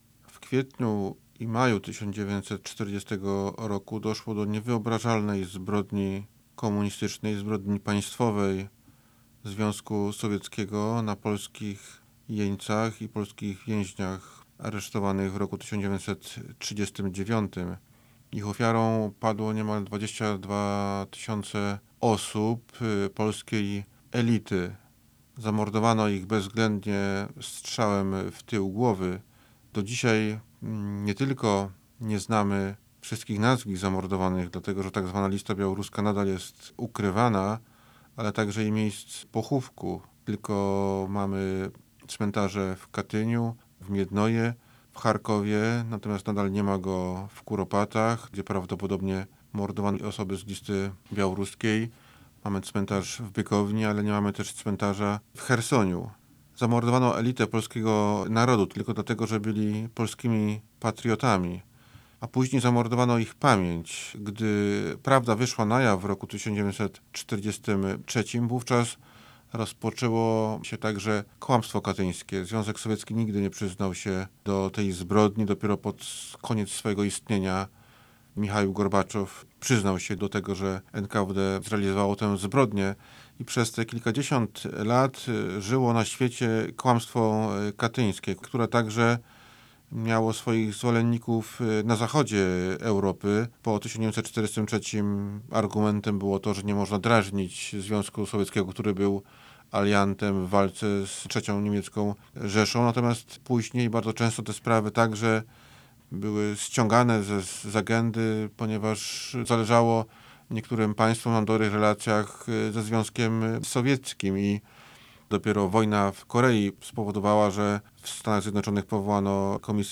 Pliki do pobrania dr Mateusz Szpytma, zastępca prezesa IPN o kłamstwie katyńskim (mp3, 3.85 MB) 07.04.2023 09:00 dr Mateusz Szpytma, zastępca prezesa IPN o kłamstwie katyńskim (wav, 69.32 MB) 07.04.2023 09:00